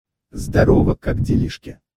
Звуки электронного голоса